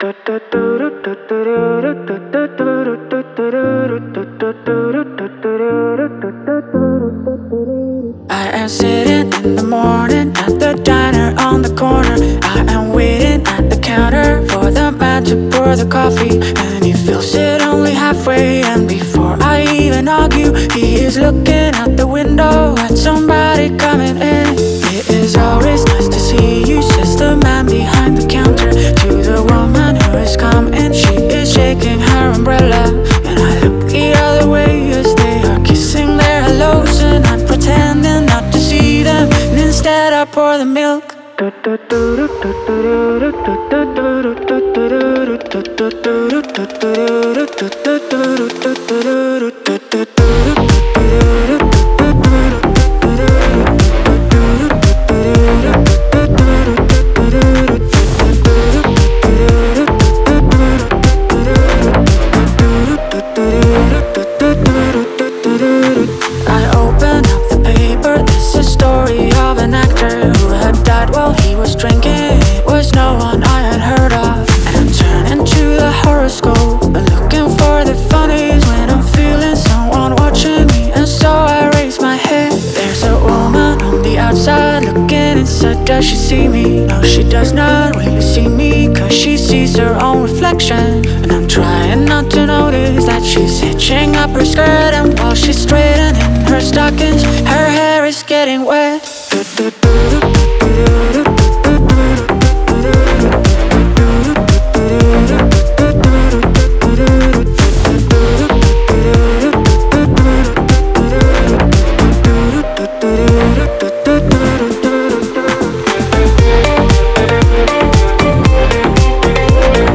это атмосферная композиция в жанре инди-поп